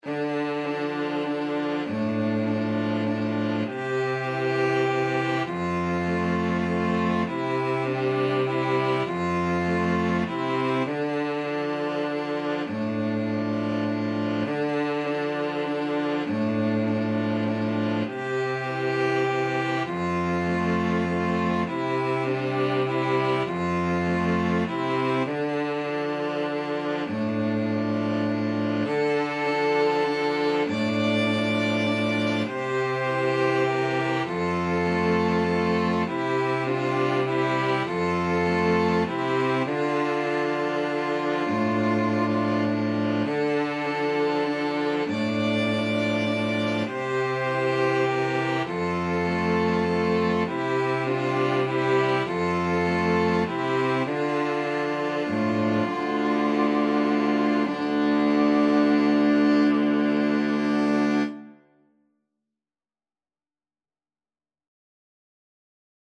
World Asia Israel Hine Ma Tov
Free Sheet music for String Quartet
Violin 1Violin 2ViolaCello
D minor (Sounding Pitch) (View more D minor Music for String Quartet )
3/4 (View more 3/4 Music)
Traditional (View more Traditional String Quartet Music)